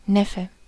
Ääntäminen
Vaihtoehtoiset kirjoitusmuodot (vanhentunut) nevew Synonyymit neve Ääntäminen RP : IPA : /ˈnɛf.ju/ GenAm: IPA : /ˈnɛf.ju/ US : IPA : [ˈnɛf.ju] RP : IPA : /ˈnɛv.ju/ Tuntematon aksentti: IPA : /ˈnɛf.juː/ IPA : /ˈnɛv.juː/